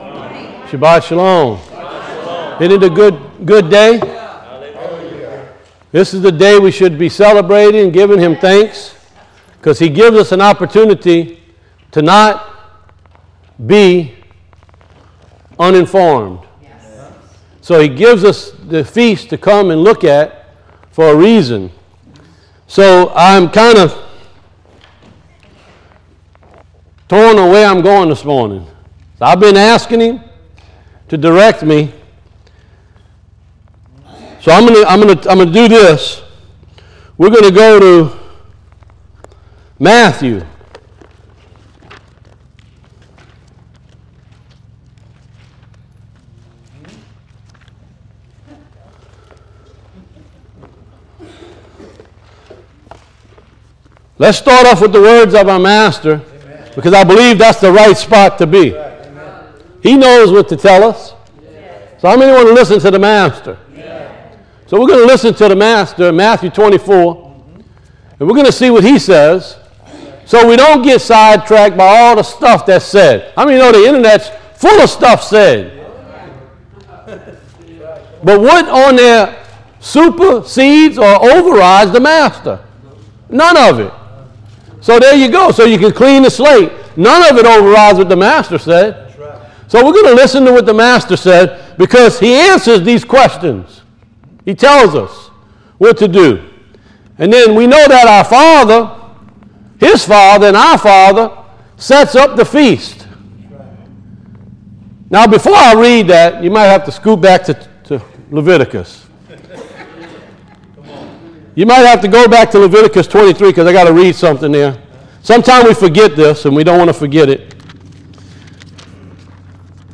Service Recordings